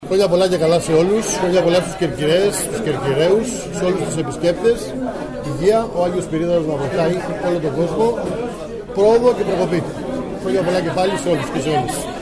Κέρκυρα: Δηλώσεις πολιτικών και αυτοδιοικητικών (audio)